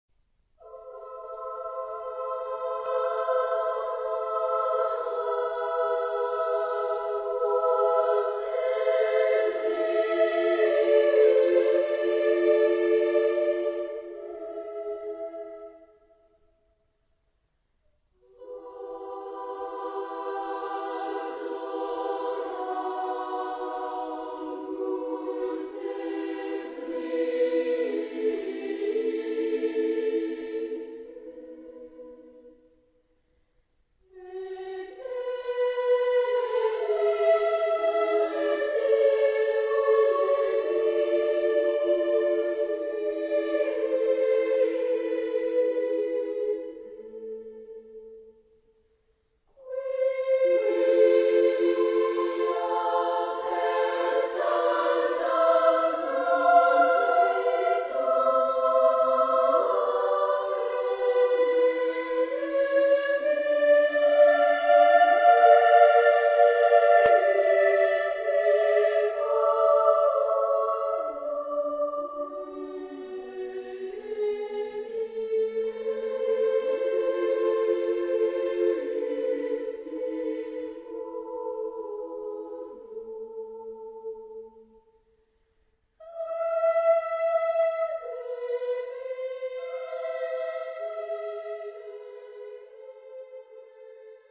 Época : Siglo 18
Género/Estilo/Forma: Sagrado ; Motete
Carácter de la pieza : moderado
Tipo de formación coral: SSA  (3 voces iguales )
Tonalidad : mi (centro tonal)